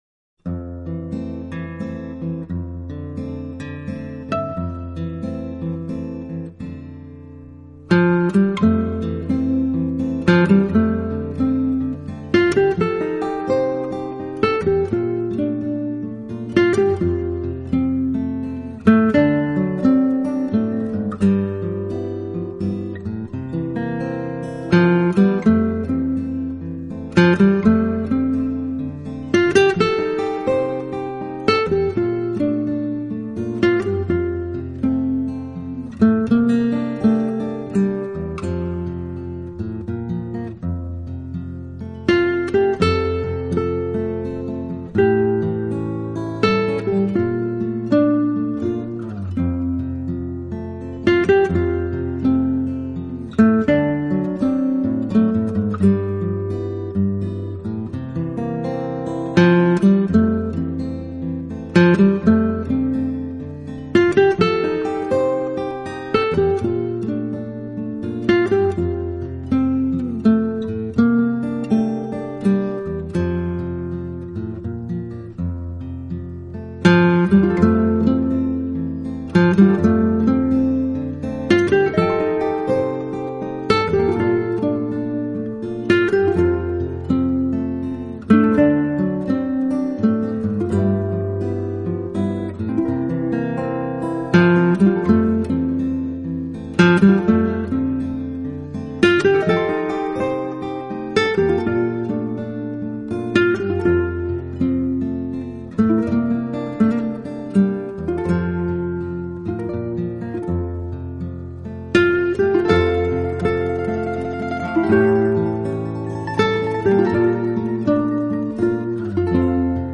音乐类型：轻音乐、纯音乐